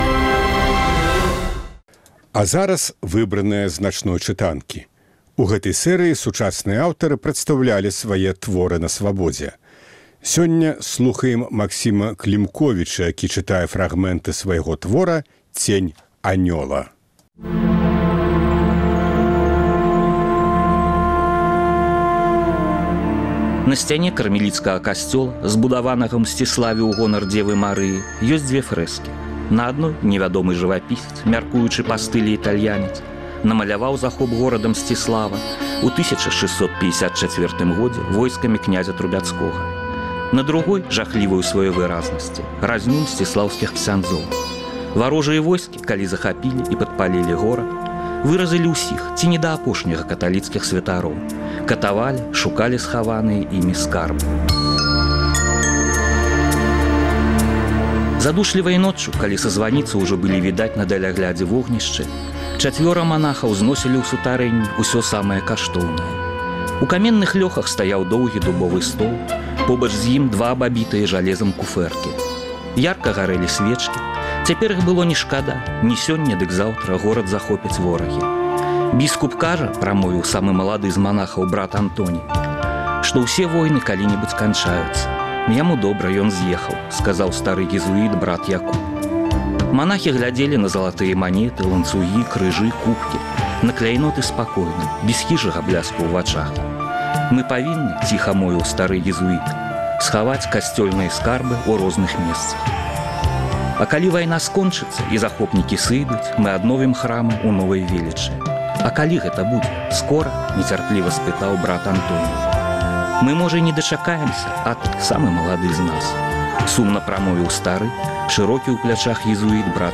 Улетку мы паўтараем перадачы з архіву Свабоды. У «Начной чытанцы» — 100 сучасных аўтараў чыталі свае творы на Свабодзе.